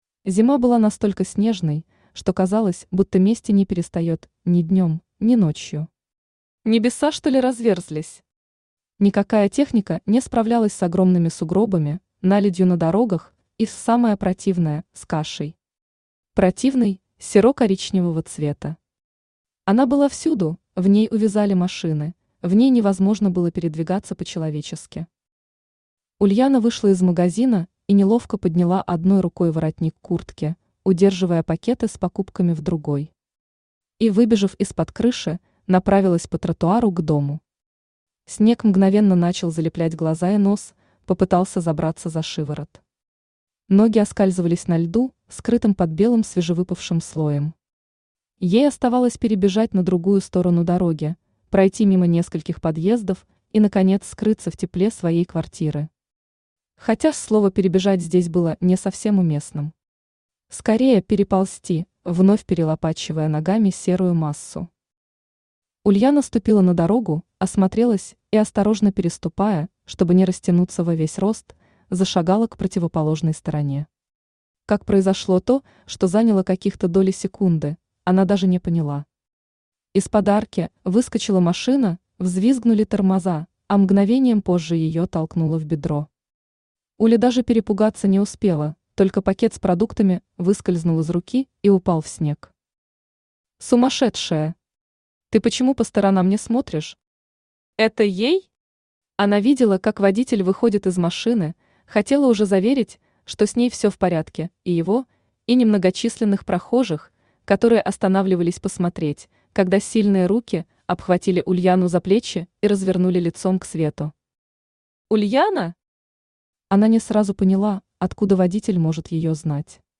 Aудиокнига Непристойное предложение Автор Полина Рей Читает аудиокнигу Авточтец ЛитРес.